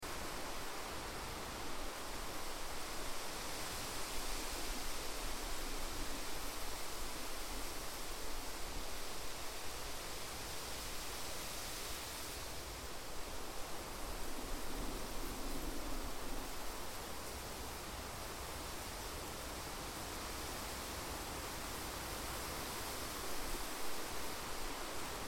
دانلود صدای باد 28 از ساعد نیوز با لینک مستقیم و کیفیت بالا
جلوه های صوتی